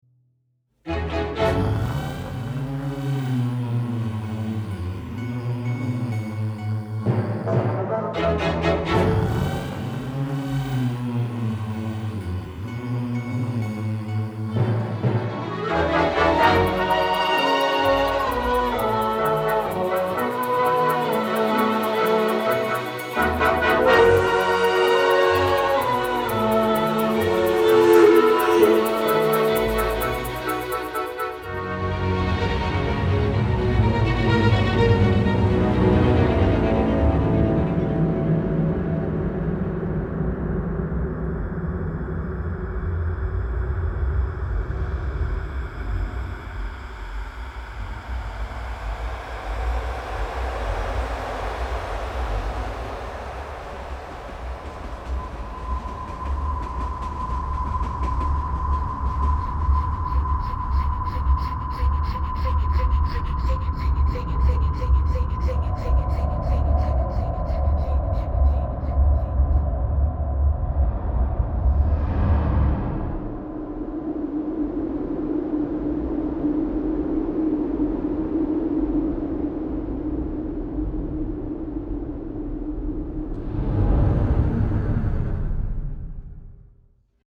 Classical Recording of the Year (orchestra/large ensemble)